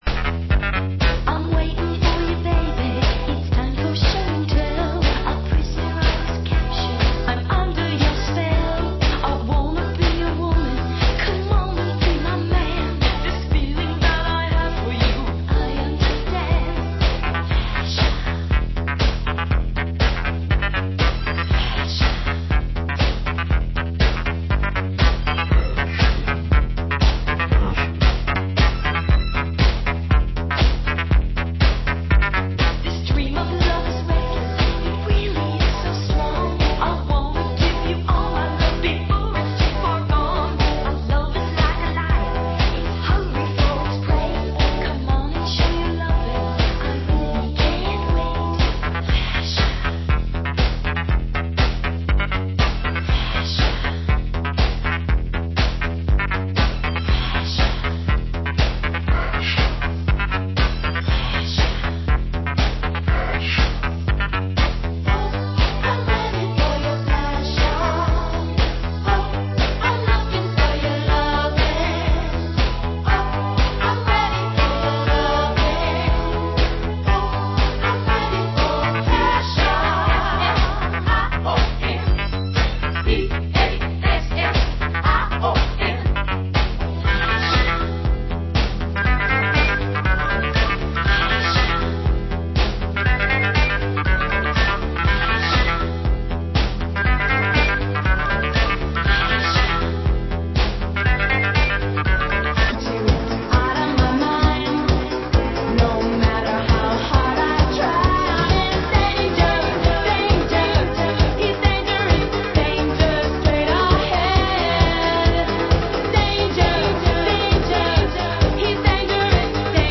Genre: Disco